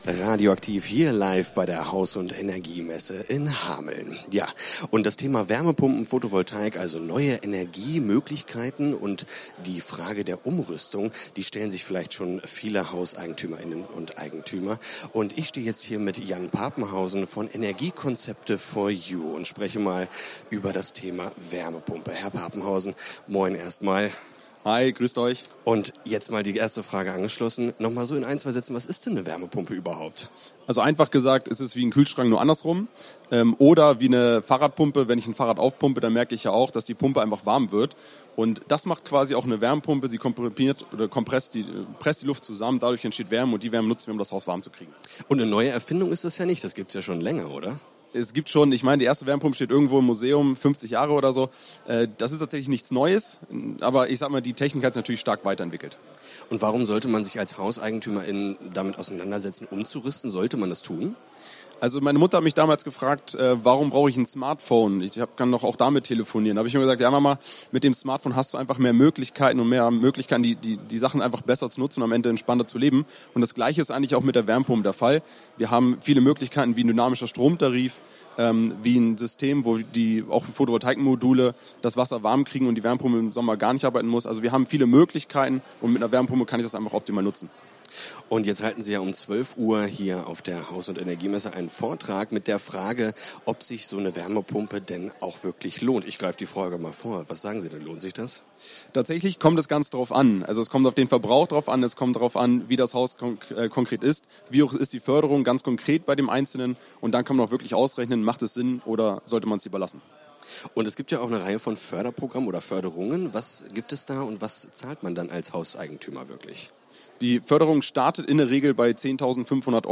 Haus und Energie Hameln: Interview mit Energiekonzepte4You
haus-und-energie-hameln-interview-mit-energiekonzepte4you.mp3